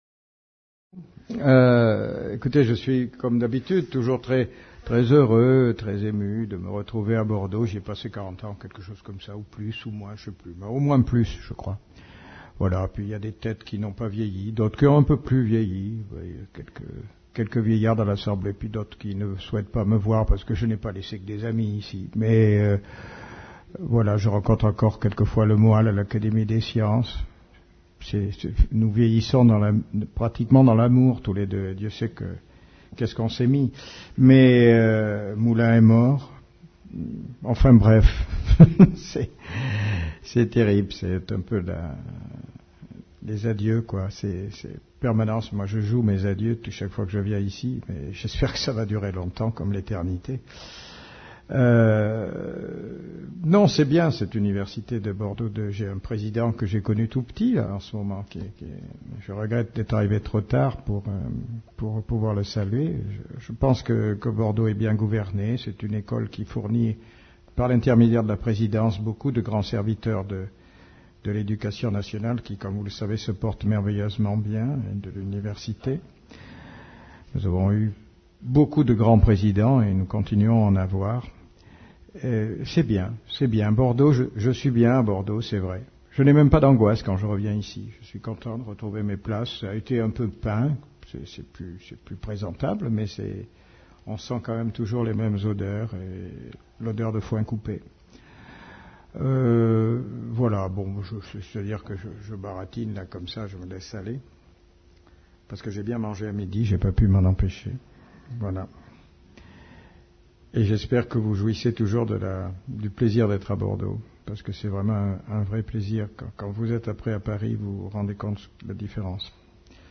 Conférence publique donnée lors du 90ème congrès de l’Association des Morphologistes le 5 juin 2008.